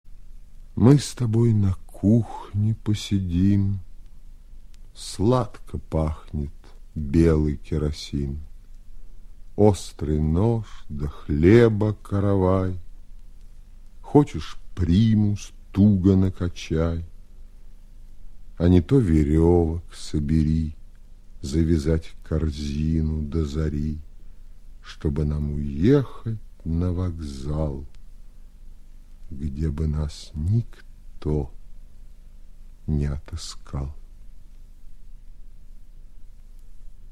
8. «Осип Мандельштам читает Михаил Козаков – Мы с тобой на кухне посидим (1931)» /
Mandelshtam-chitaet-Mihail-Kozakov-My-s-toboy-na-kuhne-posidim-1931-stih-club-ru.mp3